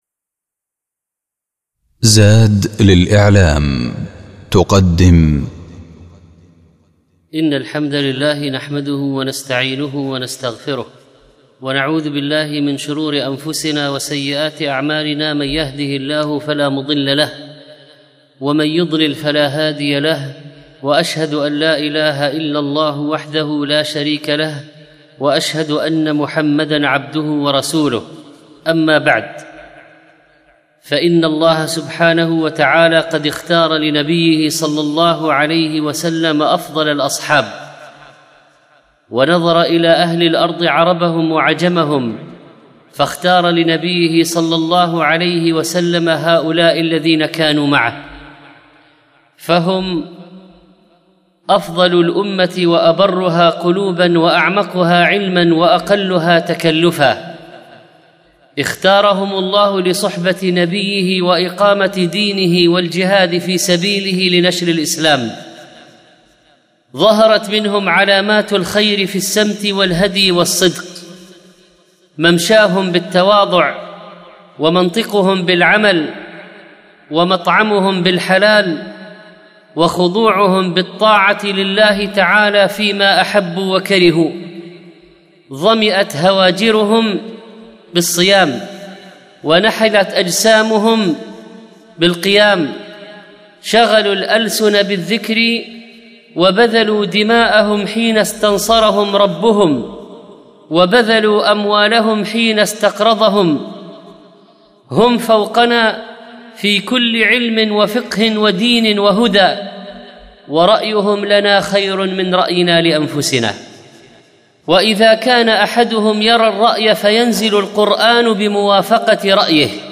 الموقع الرسمي للشيخ محمد صالح المنجد يحوي جميع الدروس العلمية والمحاضرات والخطب والبرامج التلفزيونية للشيخ
الخطبة الأولى